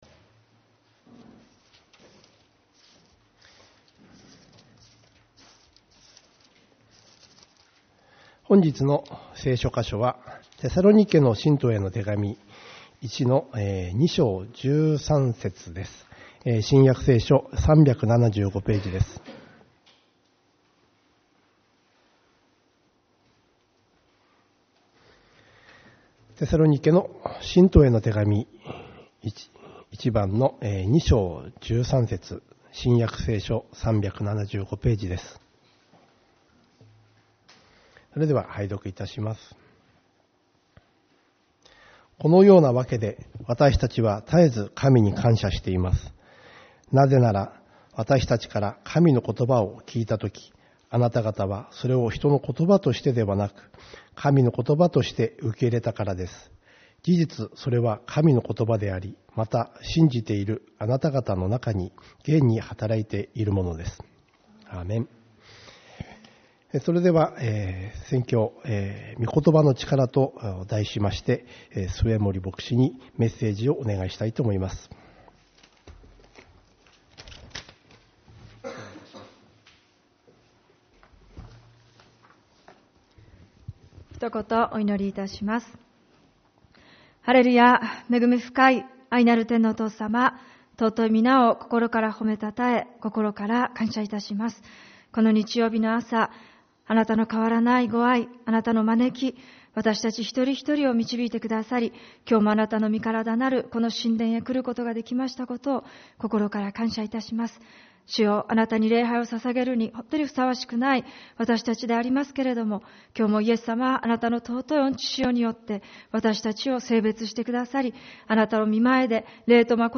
主日礼拝 「御言葉の力」